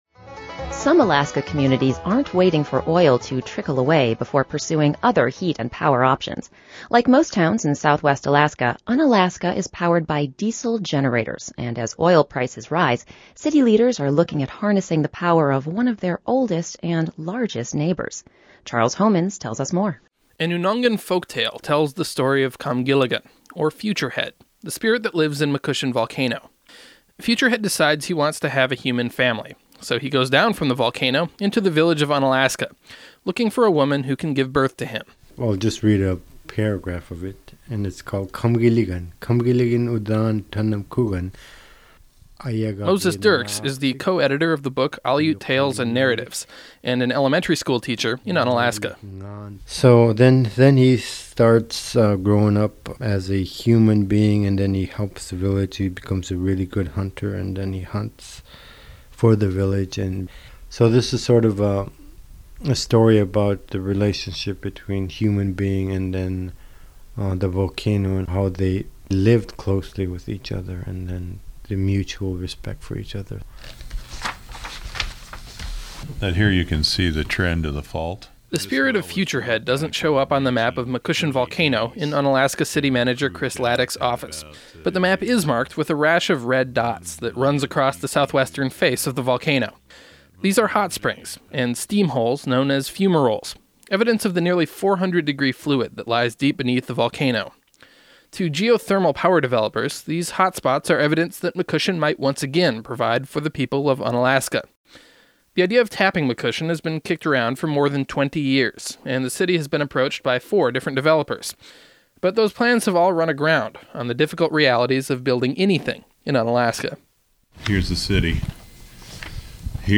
(This story first aired on APRN's "AK" program on January 6.)